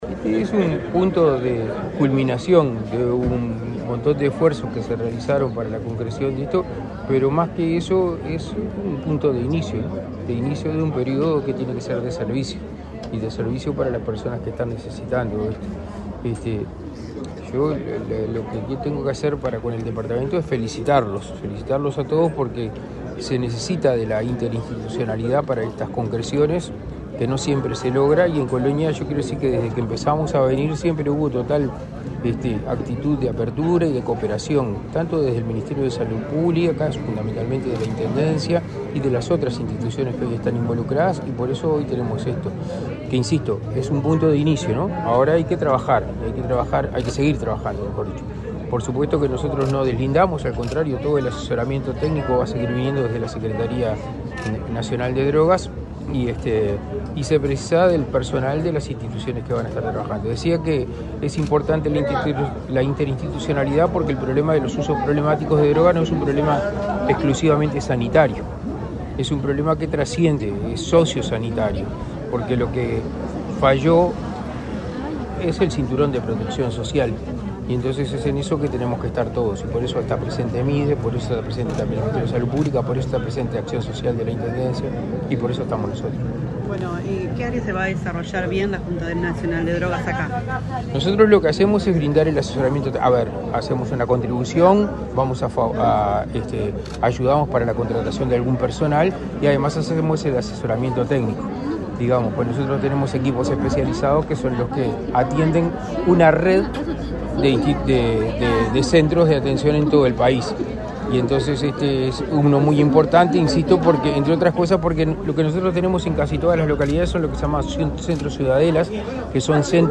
Declaraciones a la prensa del secretario nacional de Drogas, Daniel Radío
Declaraciones a la prensa del secretario nacional de Drogas, Daniel Radío 19/07/2023 Compartir Facebook X Copiar enlace WhatsApp LinkedIn Tras participar en la inauguración de un centro de prevención de adicciones, tratamiento y rehabilitación en Colonia, este 19 de julio, el secretario nacional de Drogas, Daniel Radío, realizó declaraciones a la prensa.